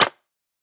Minecraft Version Minecraft Version 25w18a Latest Release | Latest Snapshot 25w18a / assets / minecraft / sounds / block / mud_bricks / step3.ogg Compare With Compare With Latest Release | Latest Snapshot
step3.ogg